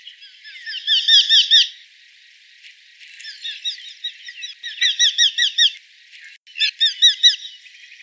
FALCO TINNUNCULUS - KESTREL - GHEPPIO
E 11°03' - ALTITUDE: 0 m. - VOCALIZATION TYPE: flight calls.
- COMMENT: The audio sample was cut off for 10 seconds (see spectrograms). Background: Nightingale.